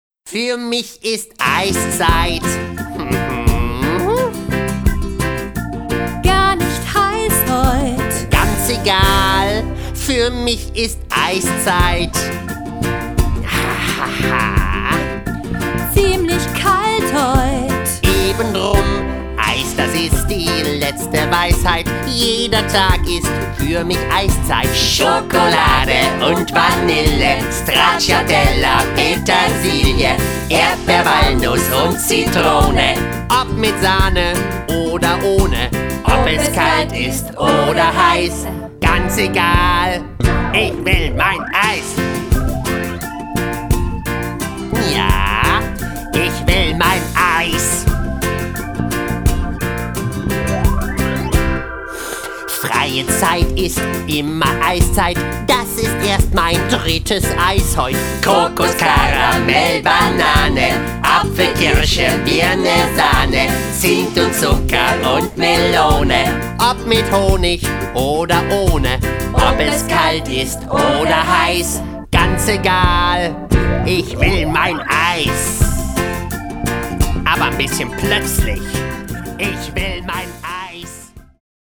Eine CD mit allen Liedern und der Geschichte zum Anhören